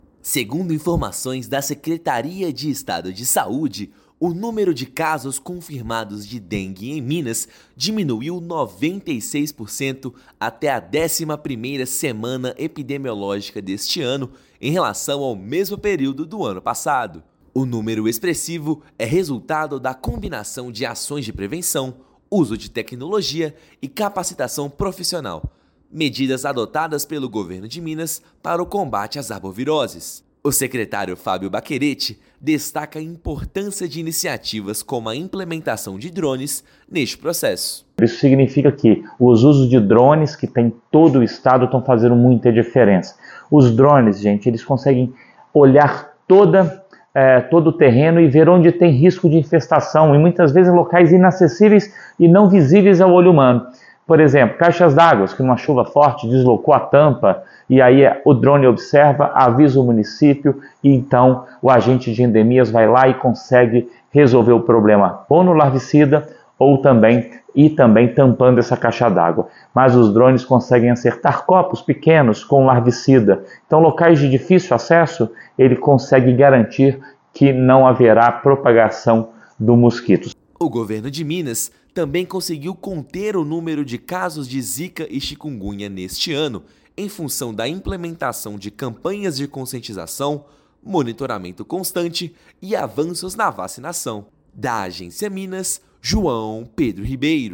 Ações de prevenção, investimentos em tecnologia e esforço conjunto preparam os municípios para enfrentar os desafios das arboviroses e proteger a saúde da população. Ouça matéria de rádio.